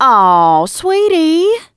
piper_kill_02.wav